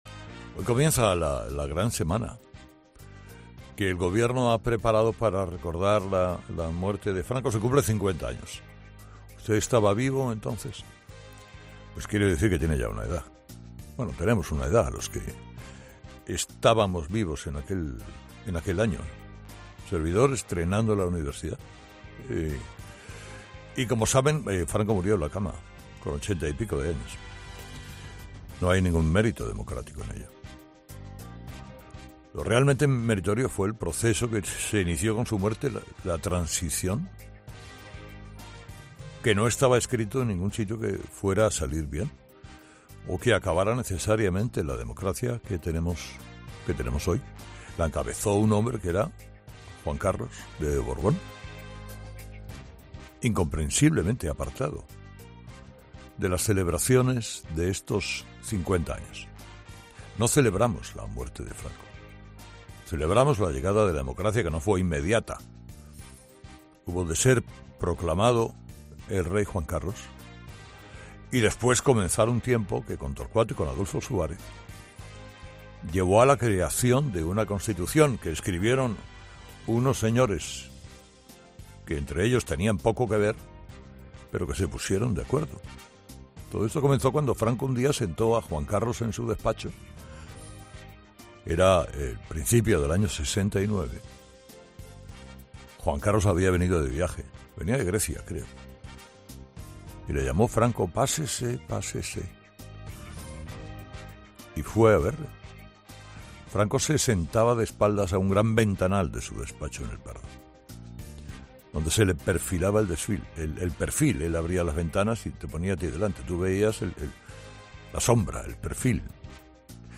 El comunicador narra en 'Herrera en COPE' el momento en el que Franco le propuso al entonces príncipe ser su sucesor y la condición que le impuso
El comunicador ha recordado en su monólogo cómo Franco le pidió a Juan Carlos I que asumiera la Jefatura del Estado
Carlos Herrera, en los estudios de COPE